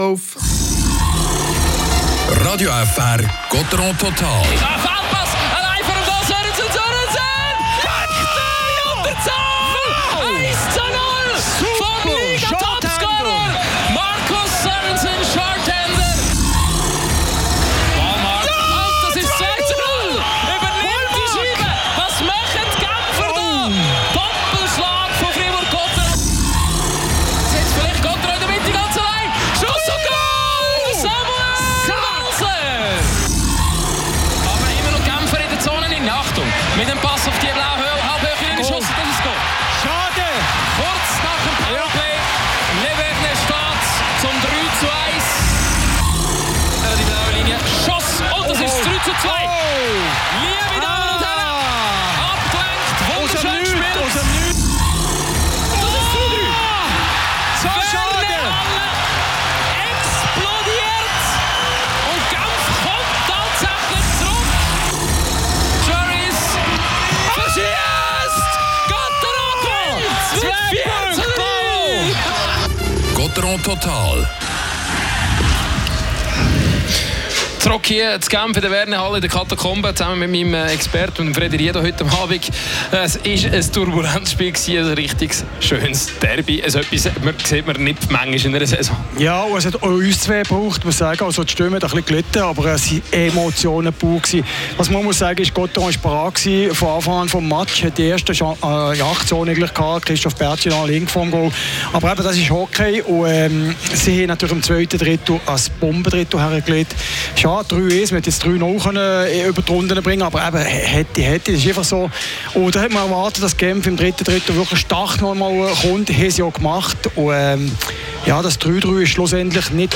Spielanalyse
Interview